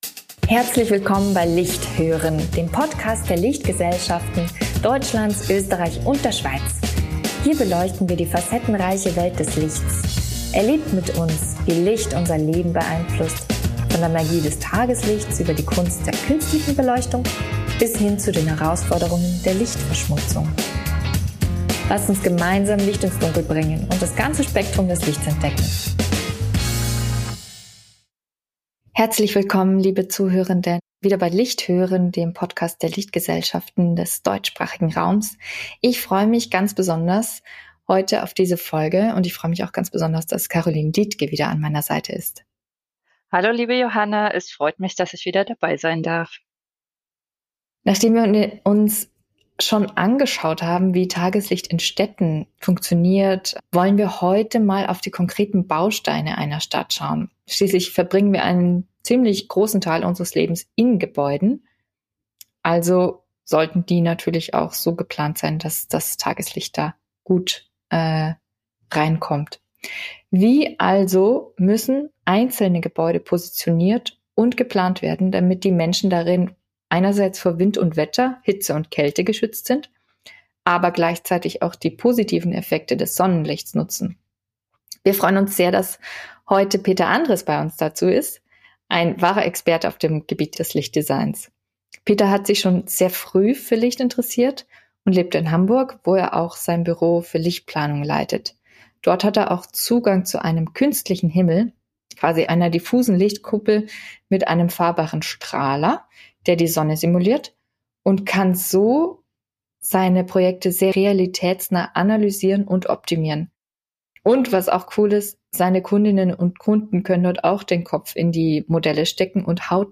Er spricht über Konflikte und Herausforderungen, die Grenzen von Simulationen mit Virtual-Reality und dass ein Gebäude kein "Schweizer Uhrwerk" ist. Außerdem erzählt der gebürtige Tiroler von den Entwicklungen der Tageslichtplanung der letzten Jahre und verrät, wie Tageslicht unser Wohlbefinden und die Gesellschaft beeinflusst – und warum es der Ausgangspunkt von allem ist.